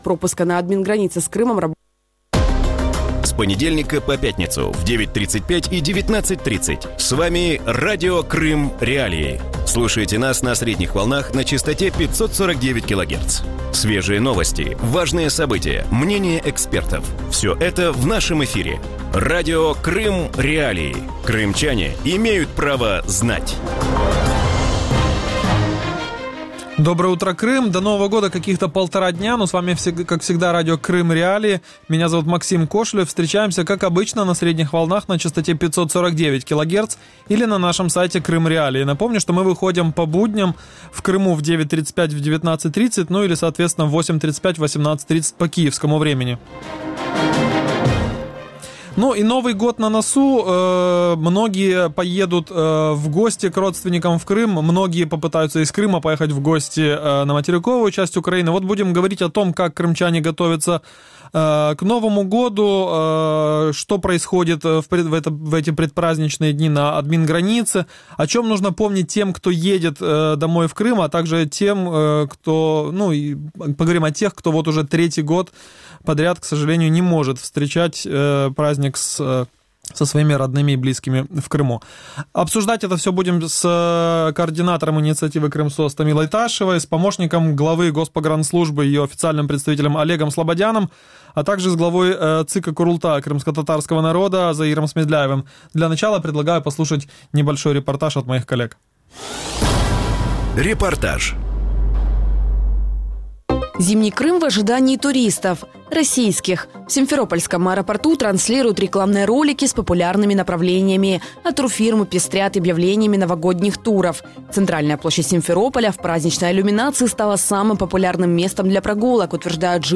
Утром в эфире Радио Крым.Реалии говорят о подготовке крымчан к Новому году в аннексированном Крыму а также на украинском материке. Что происходит на административной границе в предпраздничное время, о чем нужно помнить тем, кто едет домой в Крым, а также кто не сможет попасть в третий Новый год к родными и близким?